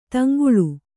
♪ taŋguḷu